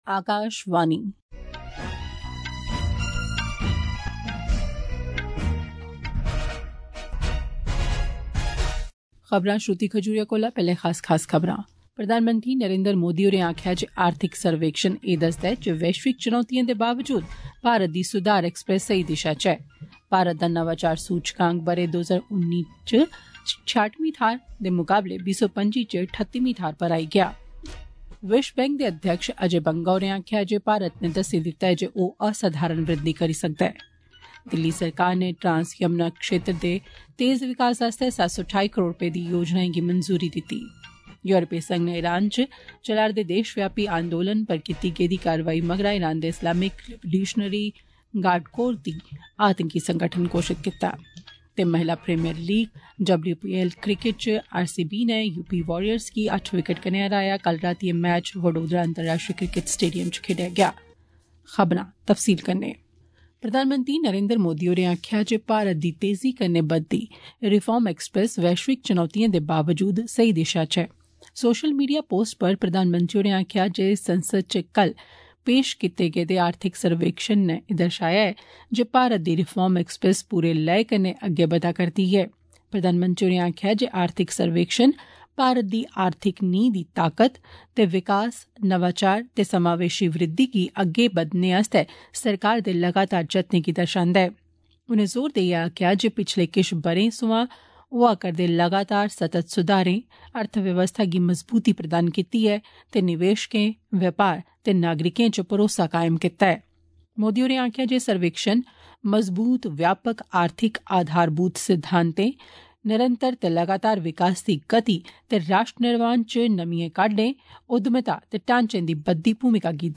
Transcript summary Play Audio Morning News
AUDIO-OF-MORNING-DOGRI-NSD-N-1.mp3